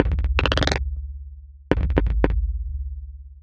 tx_perc_140_minchaotic1.wav